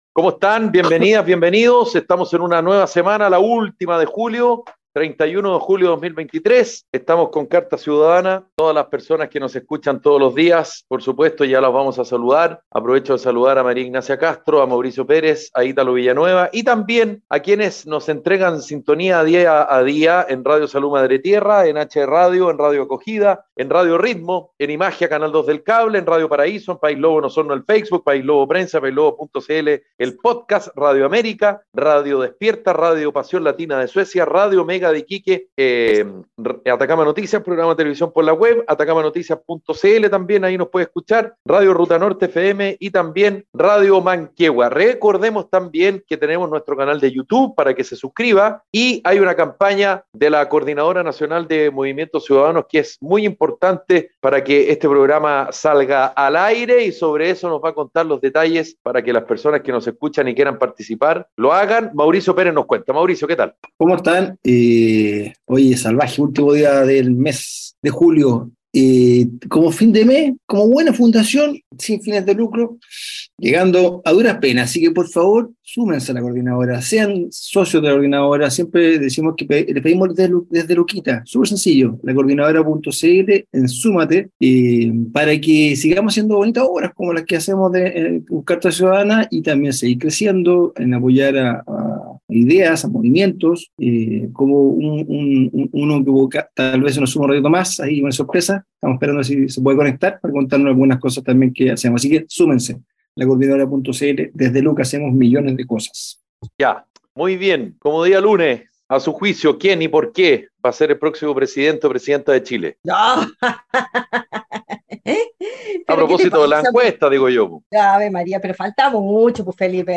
Un programa radial de conversación y análisis sobre la actualidad nacional e internacional.